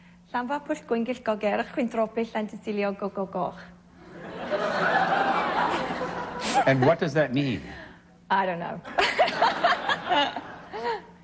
a clip of the name as interpreted by the lovely Naomi Watts on Inside the Actors Studio.